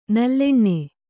♪ nalleṇṇe